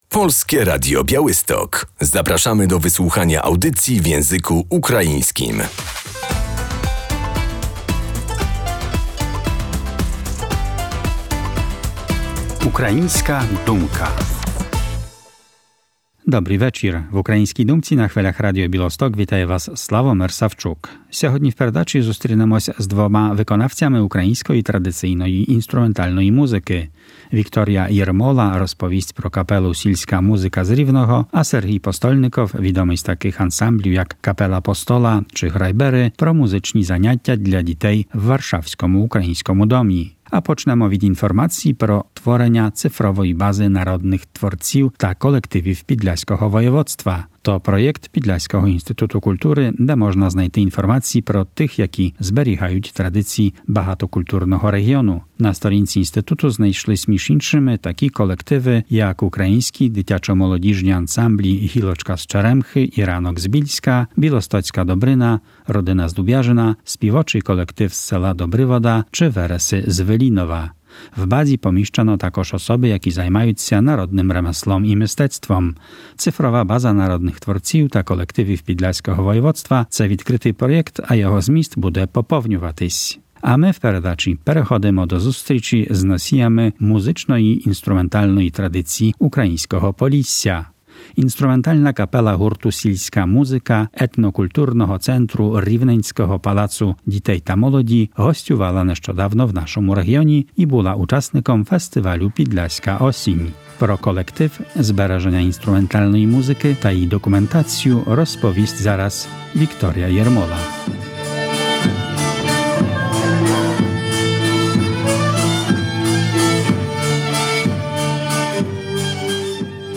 Kapela tradycyjnej muzyki „Silska muzyka” działa przy Centrum Etnokulturowym Pałacu Dzieci i Młodzieży w Równem